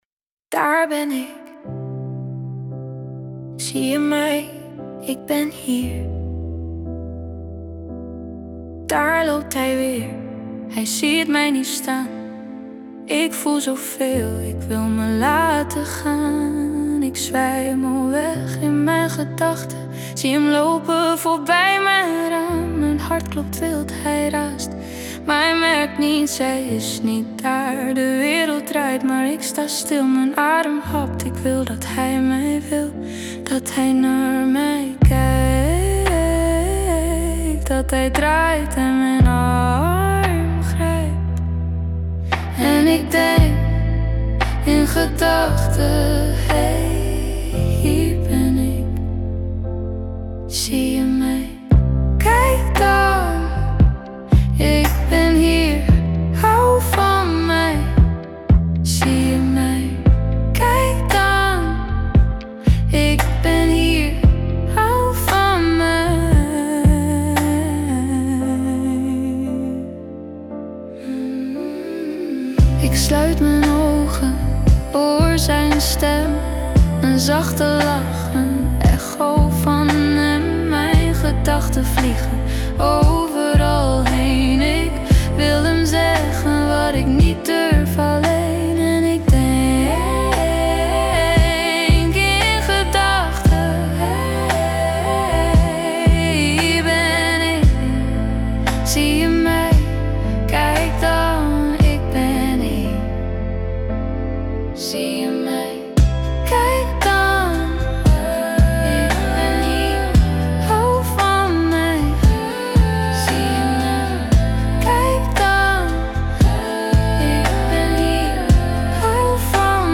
Listen to AI generated music